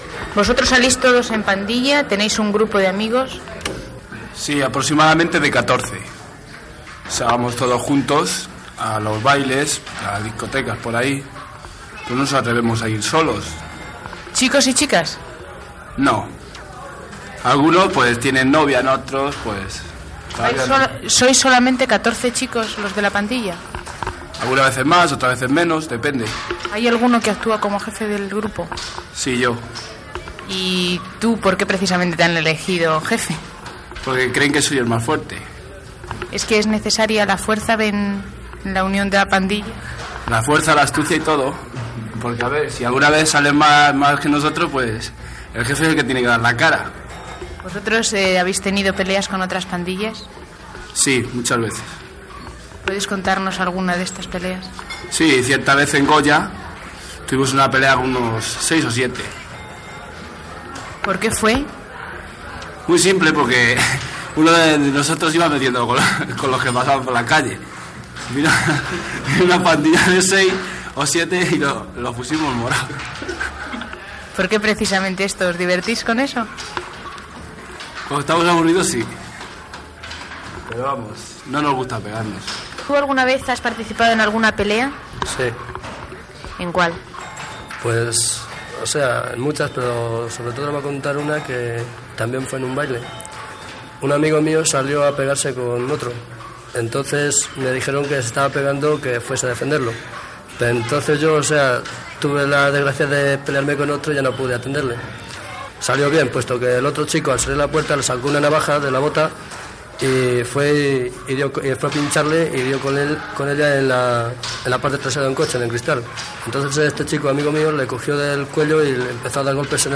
Reportatge fet a Madrid sobre les colles juvenils ("pandillas")
Informatiu